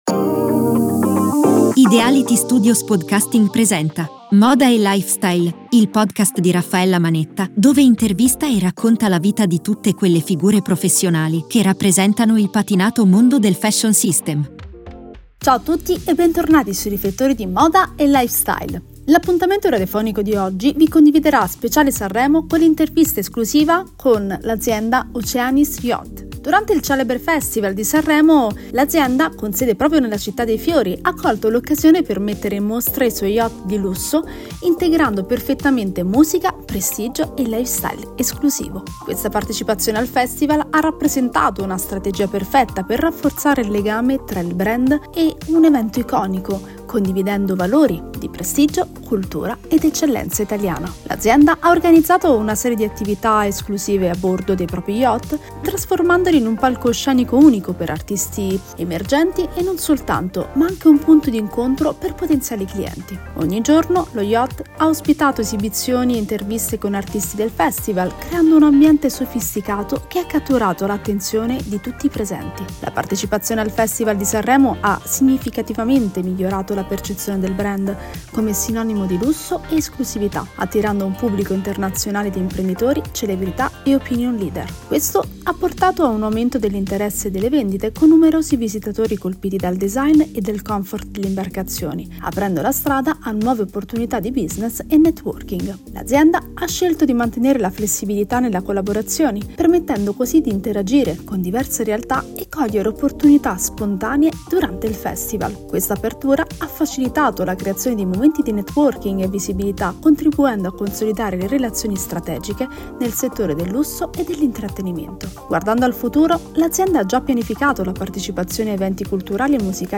Interviste Radiofoniche